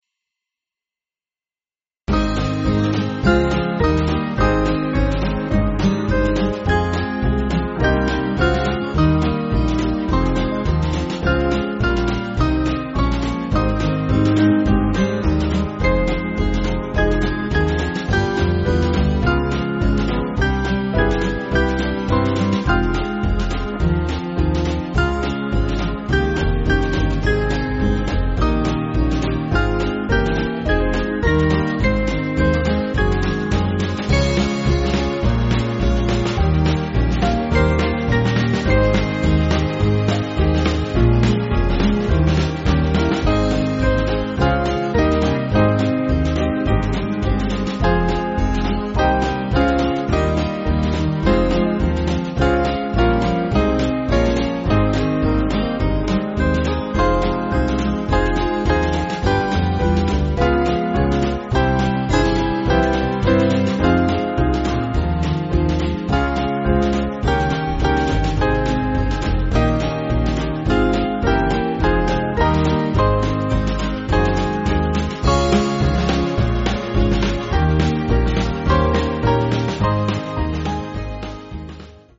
Small Band
(CM)   6/Ab